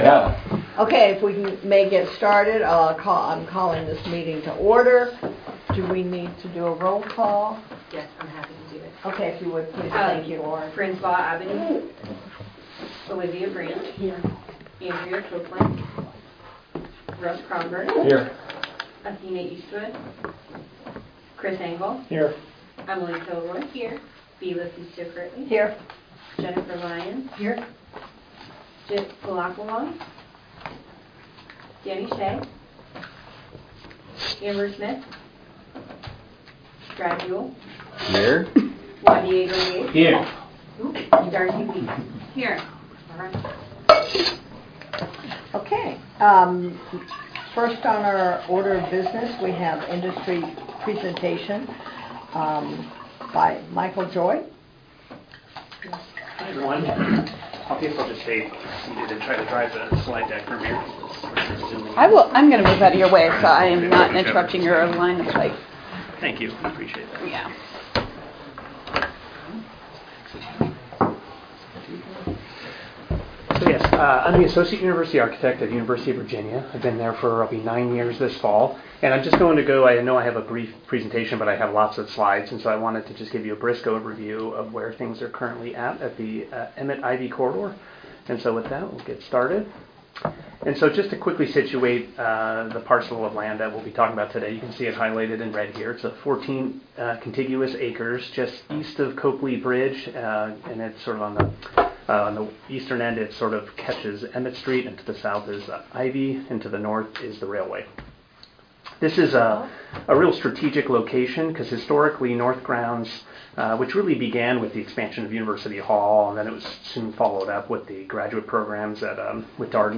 August 2025 Board Meeting Recording